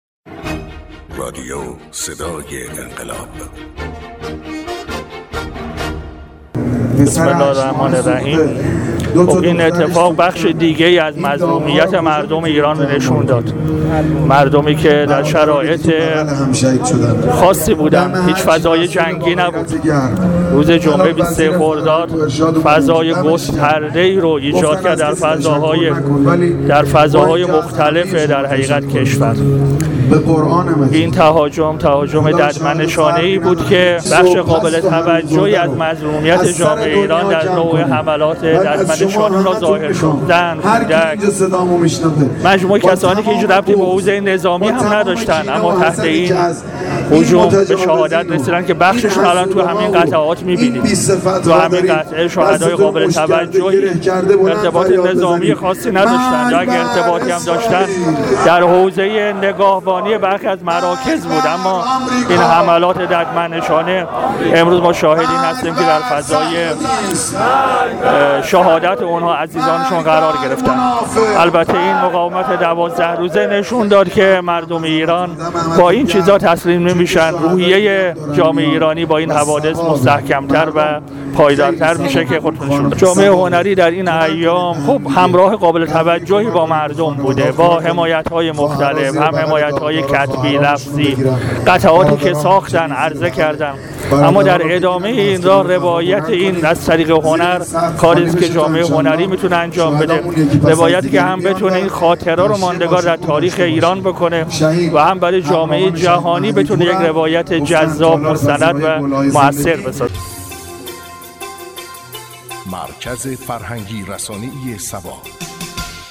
سید عباس صالحی وزیر فرهنگ و ارشاد / بازدید از مراسم تشییع شهدای بی‌گناه در بهشت زهرا